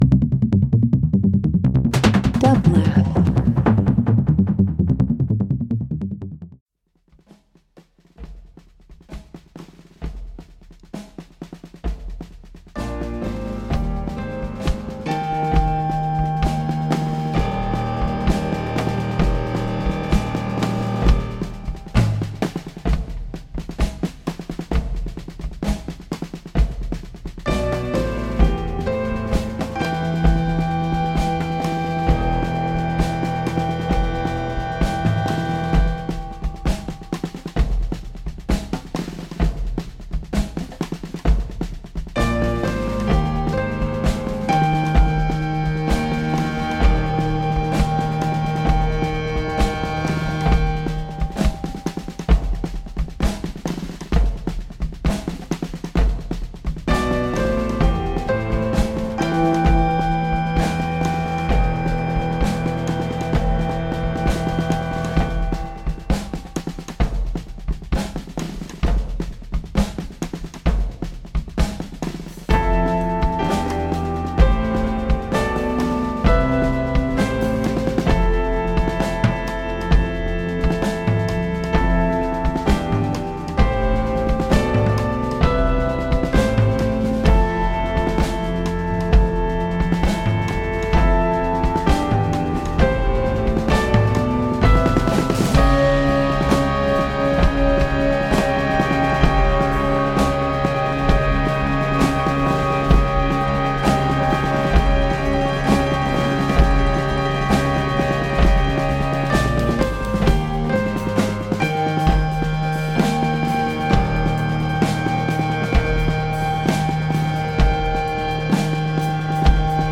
live performances